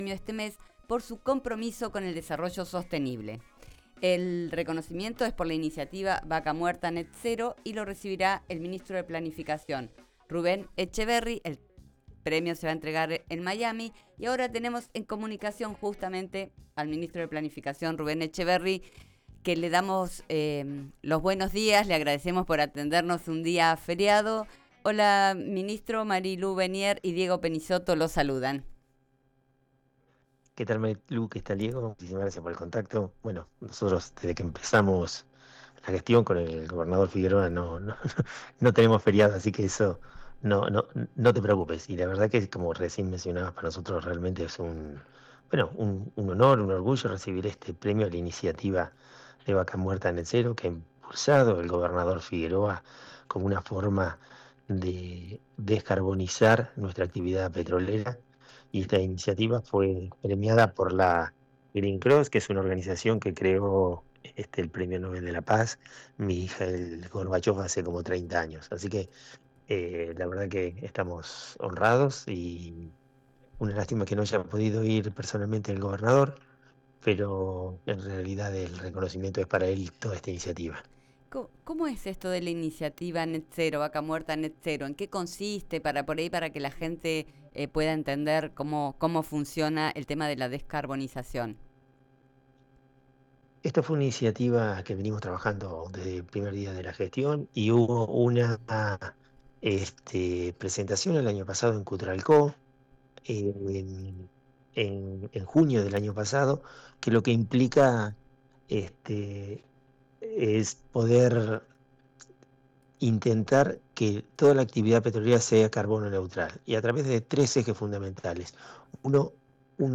Escuchá la nota con el ministro de Planificación de Neuquén, Rubén Etcheverry, en el aire de RÍO NEGRO RADIO: